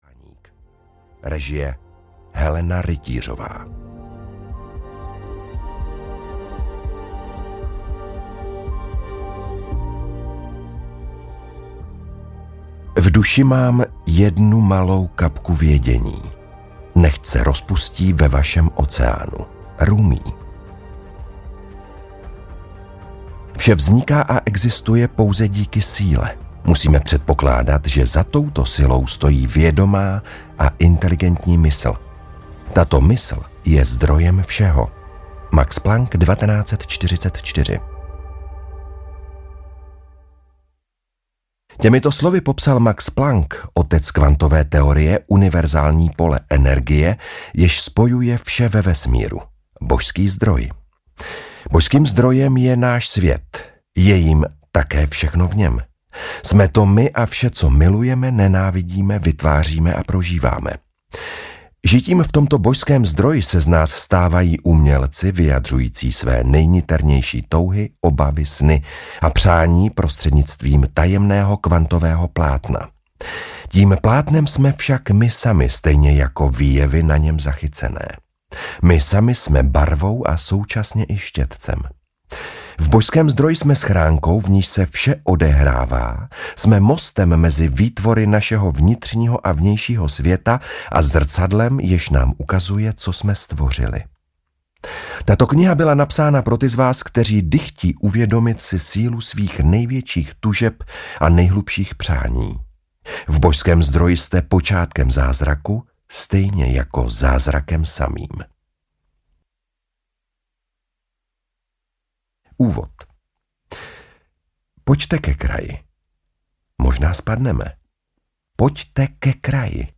Matrix: Božský zdroj audiokniha
Ukázka z knihy